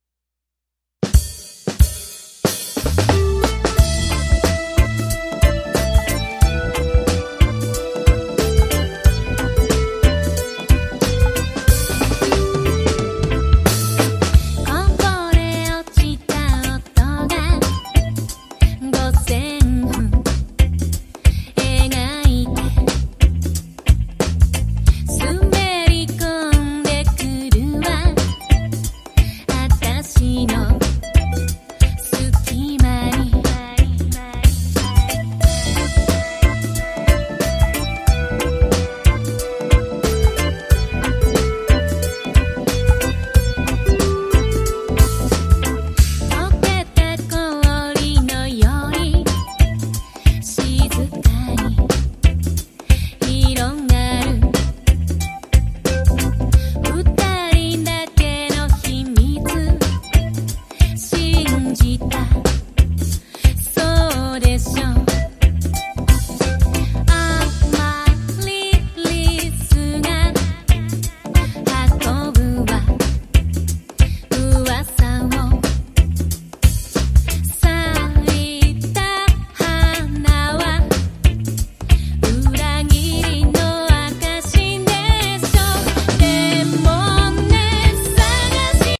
これからの暖かくなる季節、屋外で聴いても間違いなく盛り上がるダンスナンバーに仕上げられています